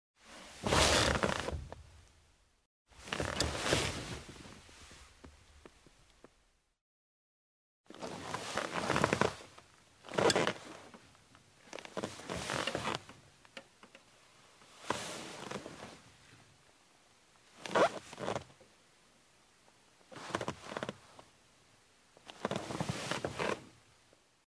Звуки мебели
Кожаный стул на котором садятся встают и двигаются